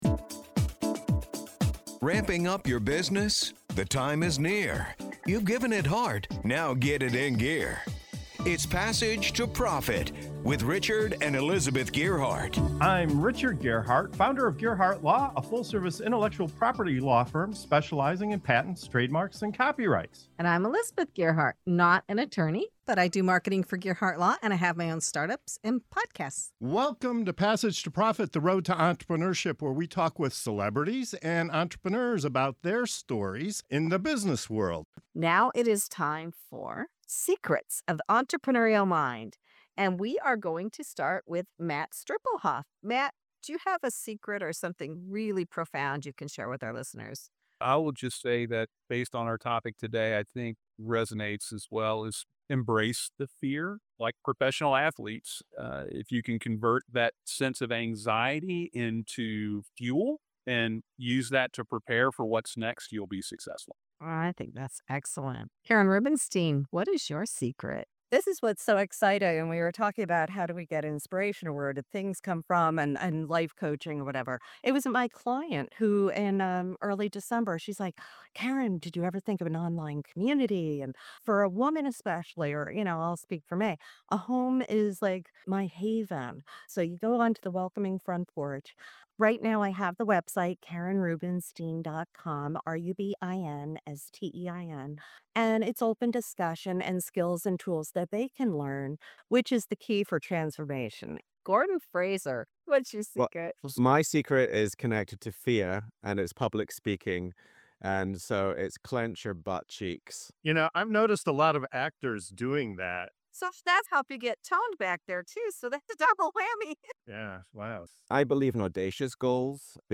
In this segment of "Secrets of the Entrepreneurial Mind" on Passage to Profit Show, our guests reveal game-changing insights on overcoming fear, setting audacious goals, and breaking free from the time-for-money trap. From turning anxiety into fuel to the surprising secret of clenching your butt cheeks for confidence, this conversation is packed with unconventional wisdom and practical strategies to help you level up in business and life.